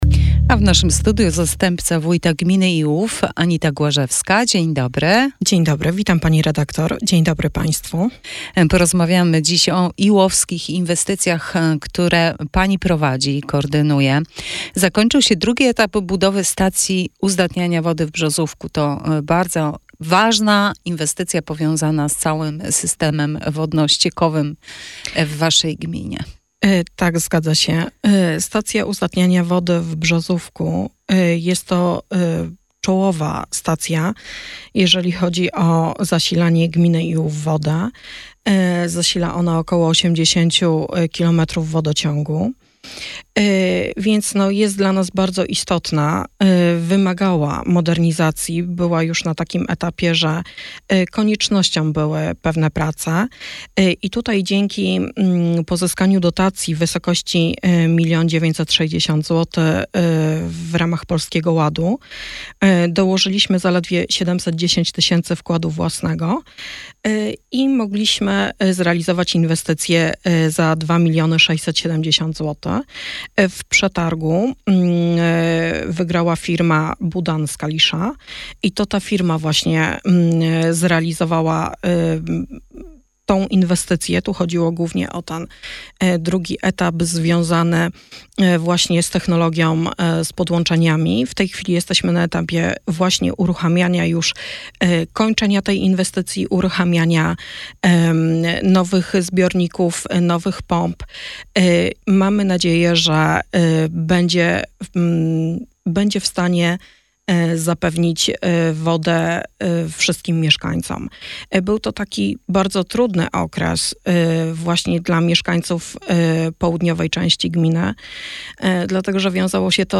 Wywiad z Anitą Głażewską, Zastępcą Wójta Gminy Iłów w Radio Sochaczew - Najnowsze - Gmina Iłów